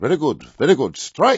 gutterball-3/Gutterball 3/Commentators/Jensen/jen_verygoodStrike.wav at 94cfafb36f1f1465e5e614fe6ed6f96a945e2483
jen_verygoodStrike.wav